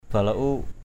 /ba-la-u:ʔ/ (d.) sấm. thunder. balauk akaok balan sa bluK a_k<K blN s% đầu tháng giêng (Chăm) sấm động.
balauk.mp3